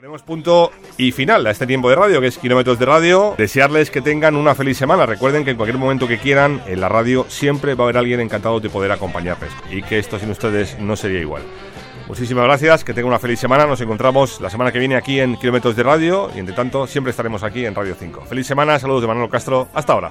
Comiat del programa Gènere radiofònic Musical